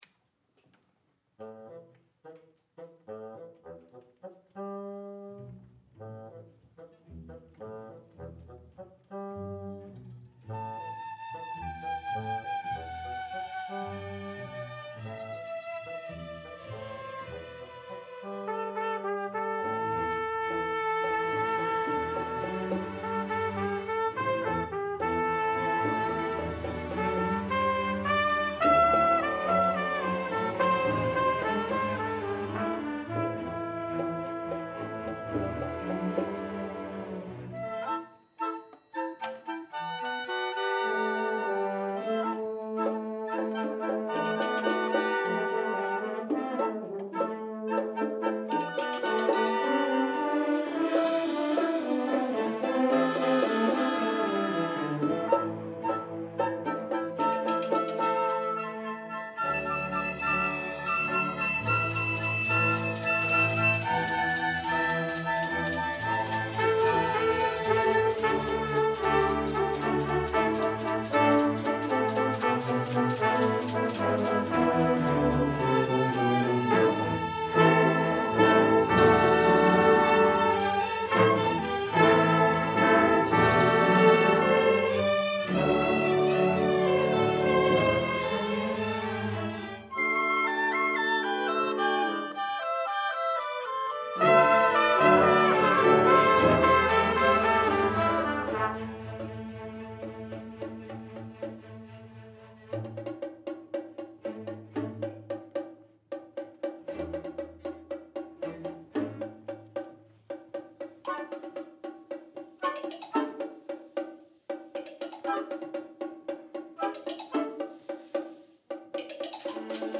for Orchestra (2016)